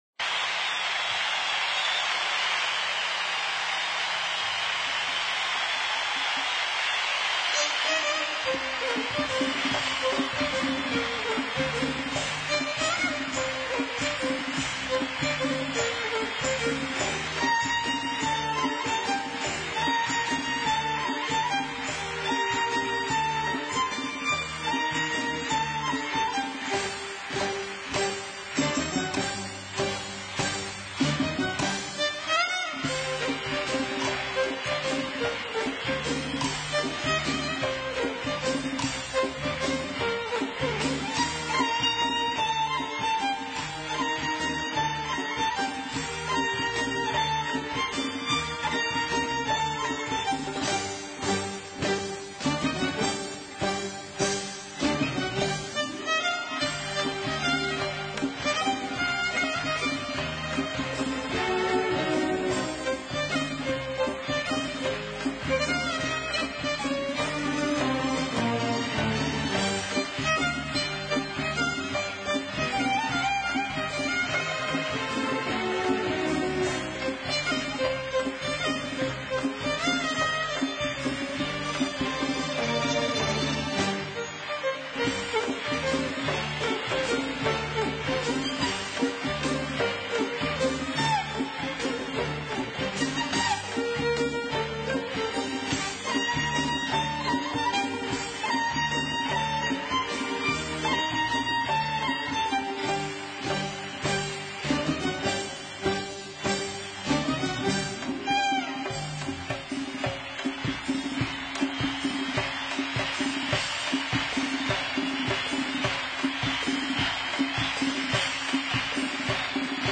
IRANIAN & Nostalgia